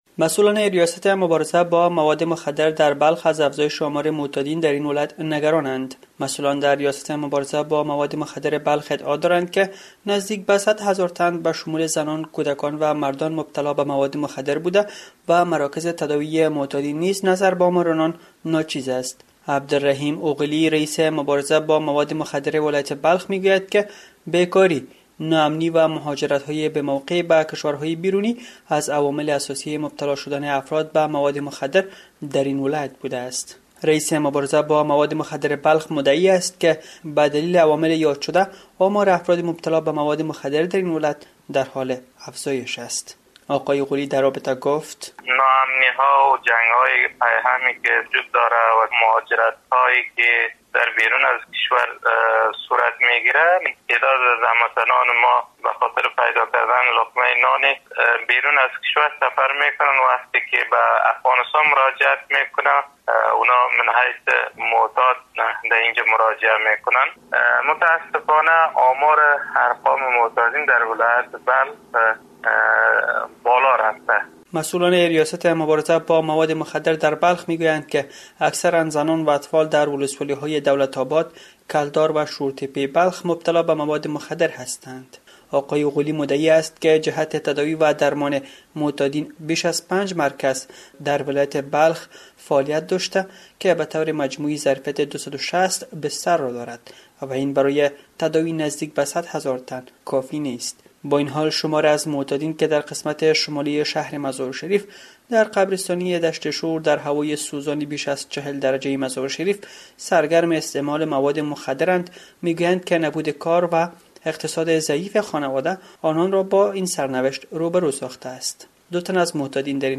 دو تن از معتادین در این باره گفتند: